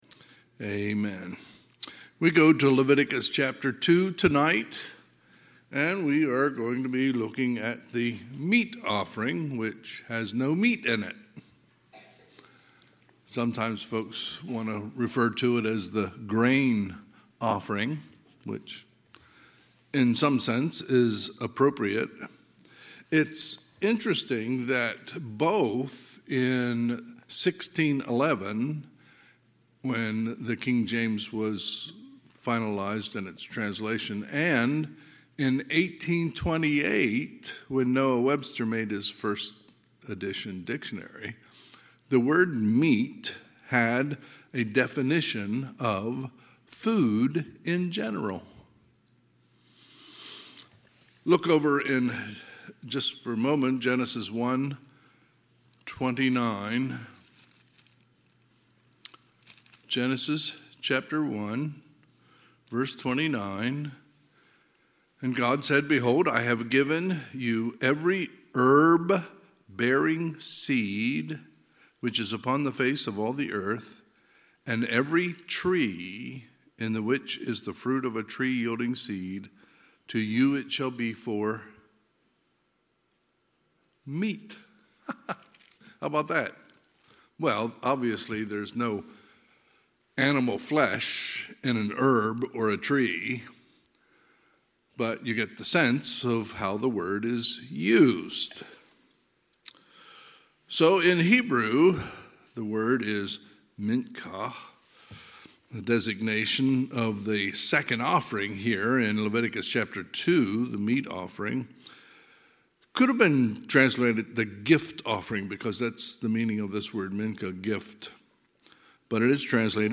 Sunday, March 24, 2023 – Sunday PM